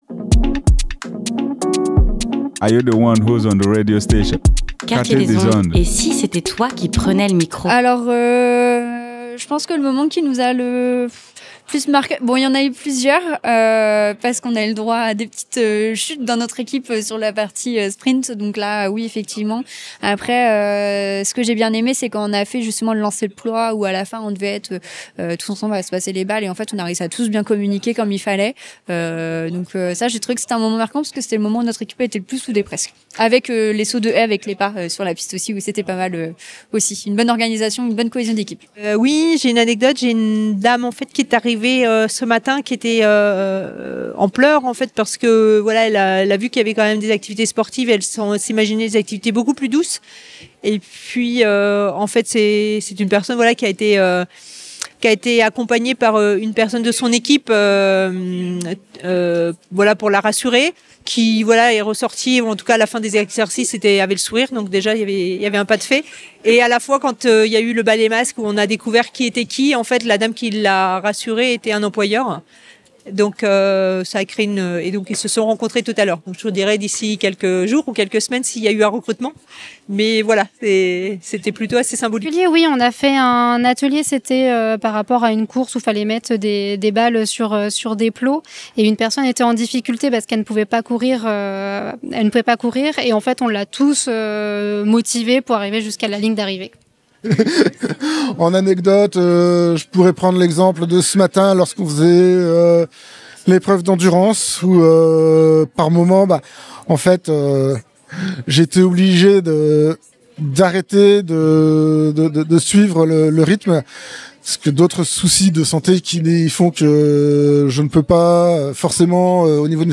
REPORTAGE : Quartier des Ondes s’est rendu au Stade vers l'emploi, un événement à la croisée du sport et de l’insertion professionnelle.